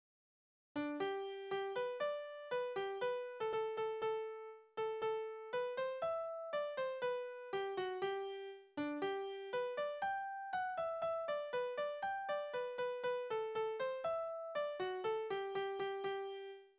Balladen: Die Verlassene stirbt vor Schmerzen
Tonart: G-Dur
Taktart: 2/4
Tonumfang: Oktave, Quarte